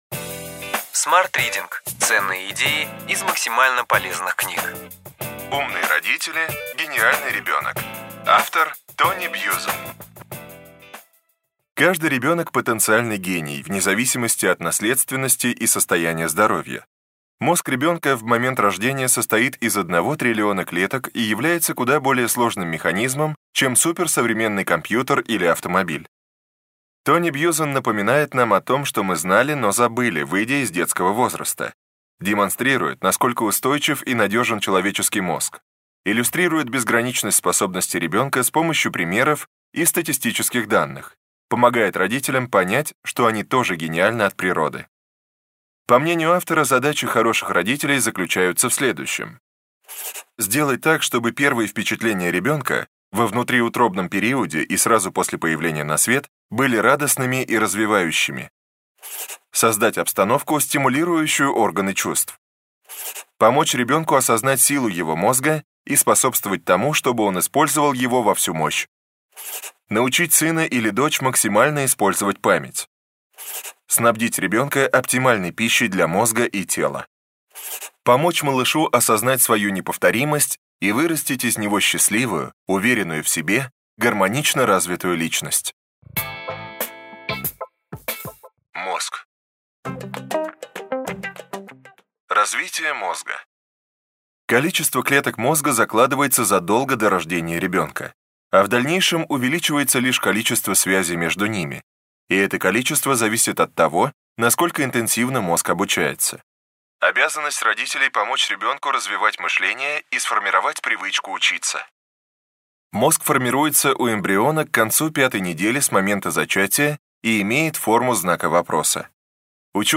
Аудиокнига Ключевые идеи книги: Умные родители – гениальный ребенок.